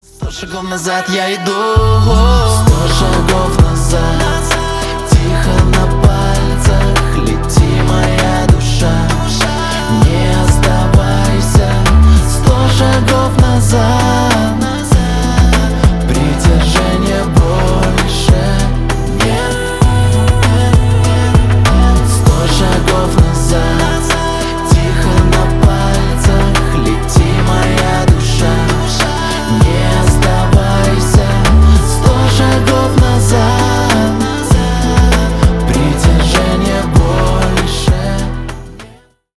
Поп Музыка
кавер # грустные